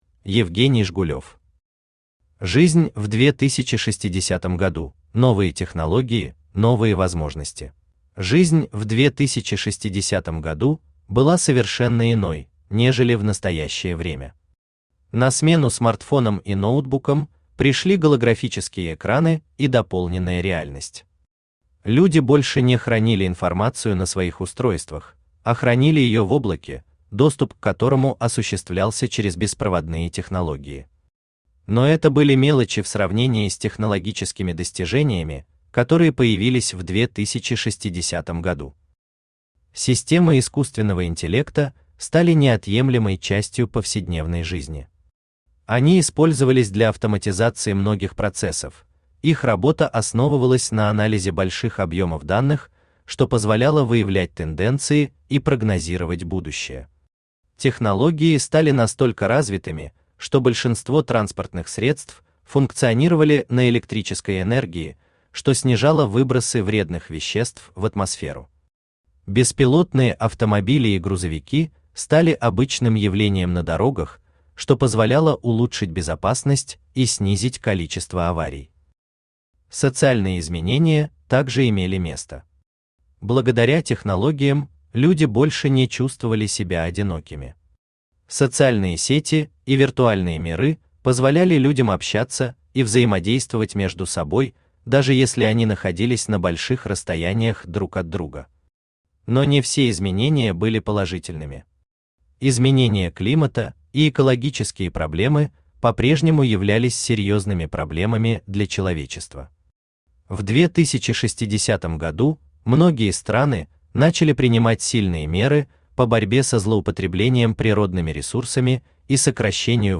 Aудиокнига Жизнь в 2060 году: новые технологии, новые возможности Автор Евгений Жгулёв Читает аудиокнигу Авточтец ЛитРес.